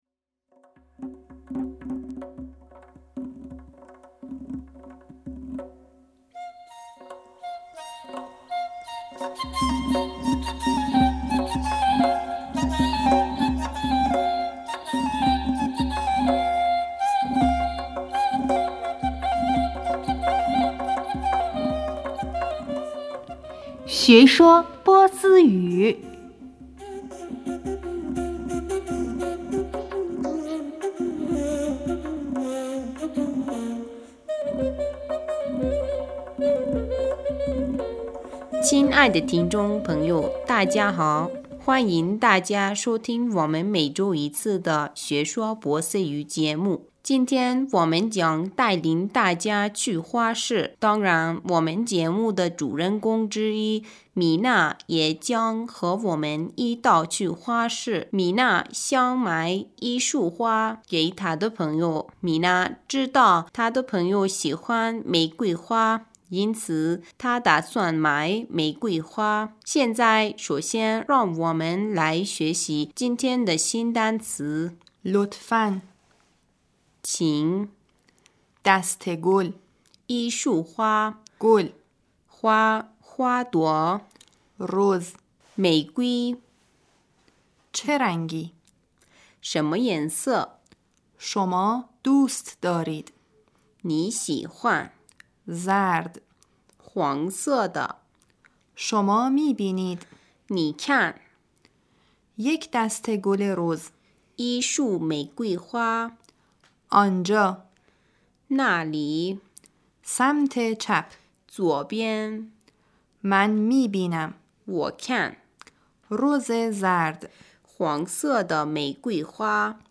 学说波斯语 27 亲爱的听众朋友大家好，欢迎大家收听我们每周一次的学说波斯语节目。